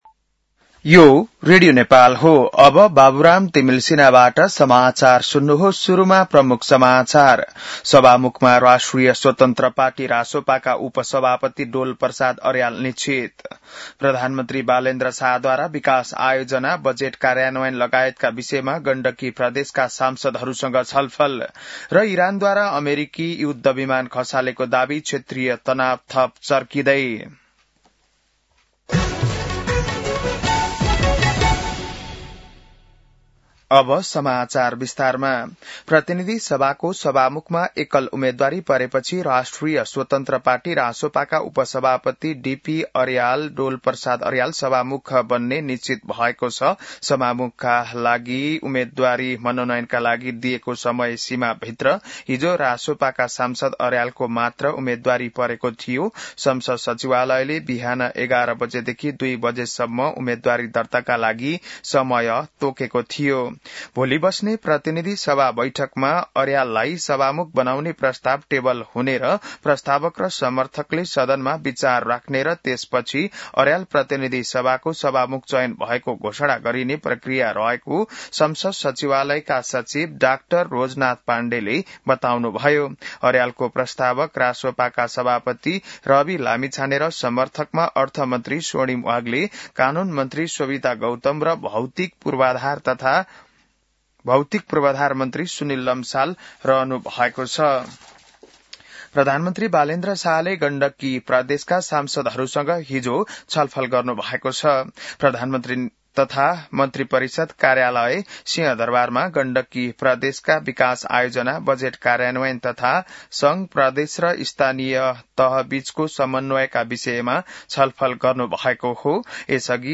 बिहान ९ बजेको नेपाली समाचार : २१ चैत , २०८२